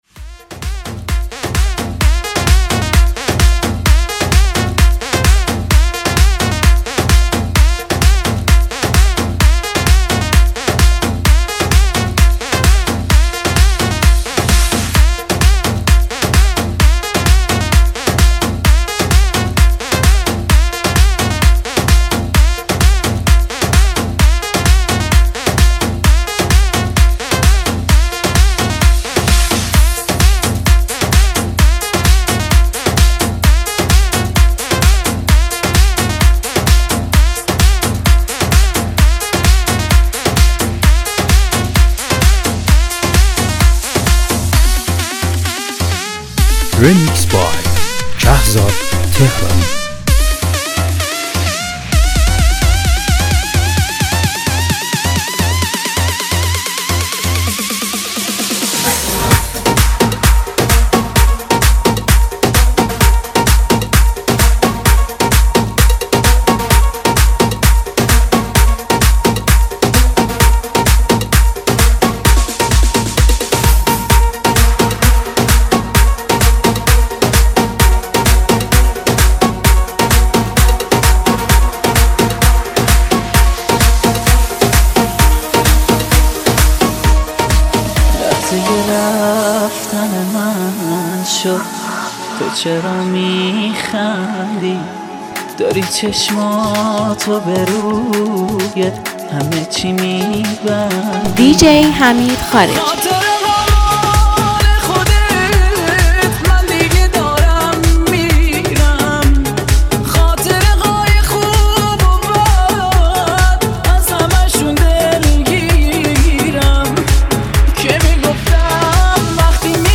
فضای رمانتیک و دلنشینی رو برای شما می‌سازه.